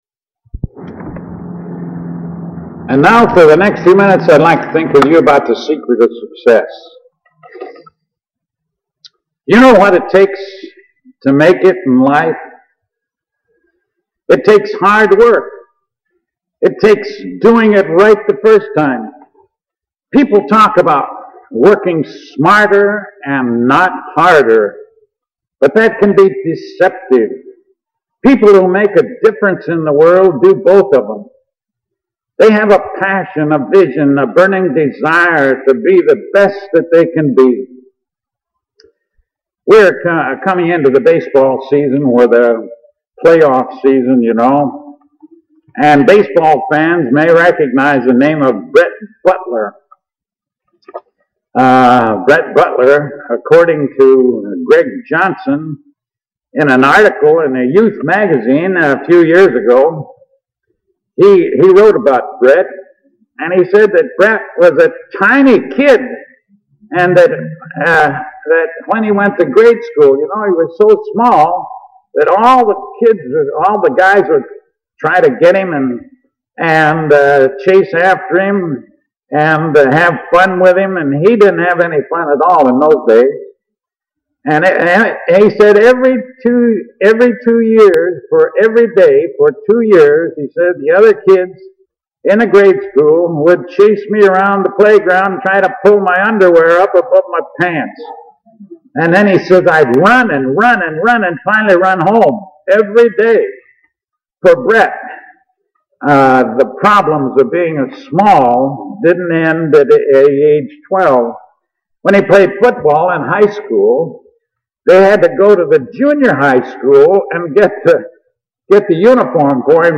The Secret of Sucess Filed Under: All Christian Sermons , Goals In Life